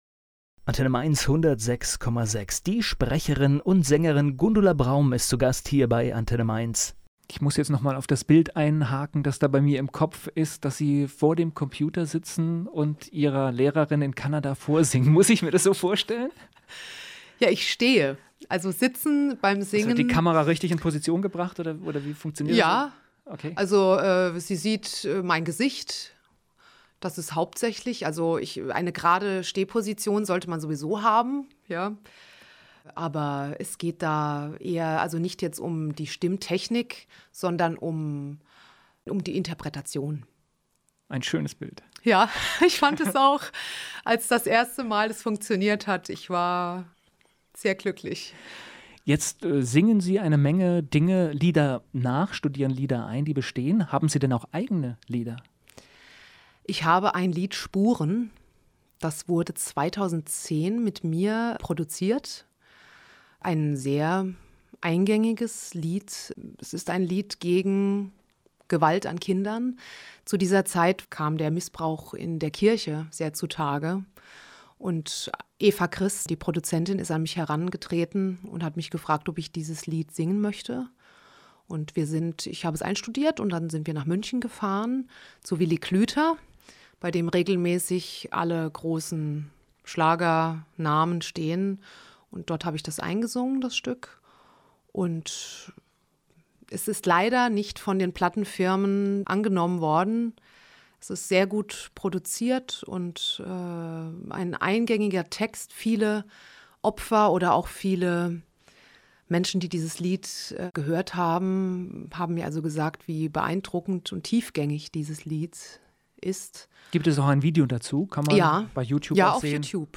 Interview starten